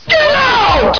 The victim's screen will fadeout from red and it will play a diferent sound effect
getout.wav